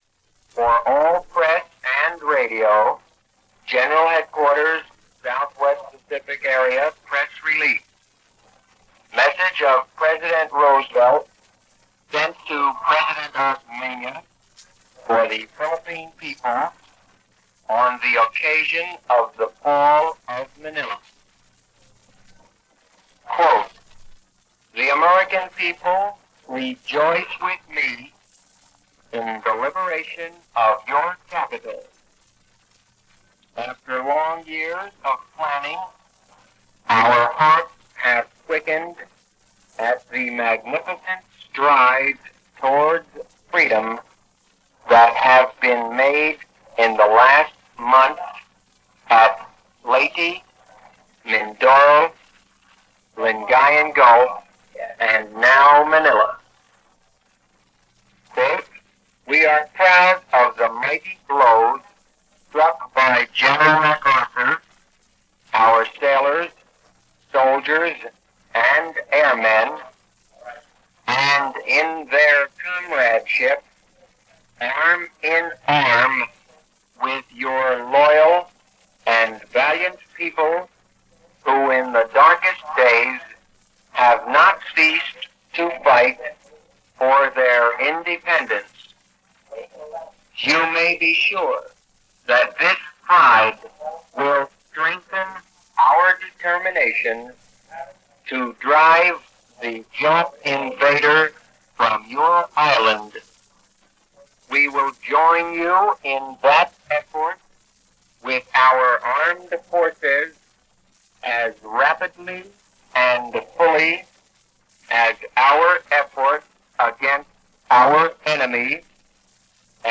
Here is a message, delivered by President Roosevelt and General Douglas MacArthur to the people of Manila the day the allies took control of the city.